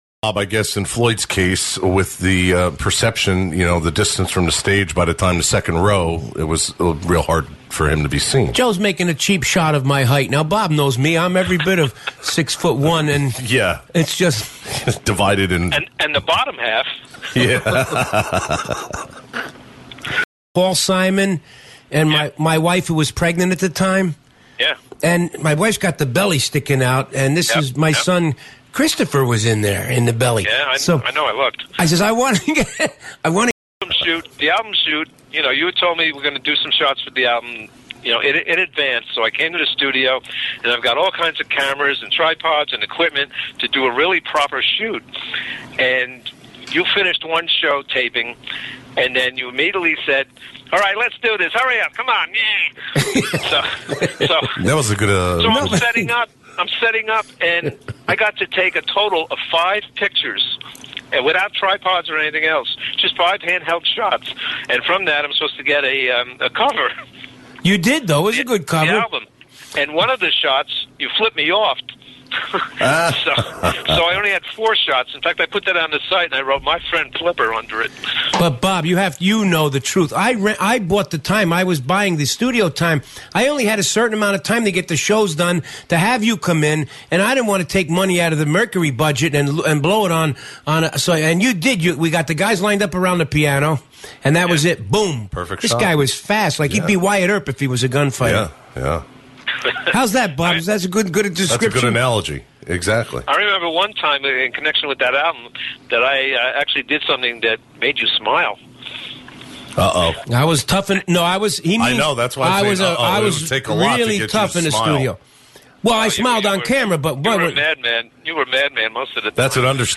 They accepted random calls on the air and I called that night.
This mp3 that I recently came across isn’t the entire call………….I’m guessing it’s just the favorite bits that I slapped together back then. And there’s a glitch just before I mentioned “cards”…………that was about baseball cards (and why I no longer have mine).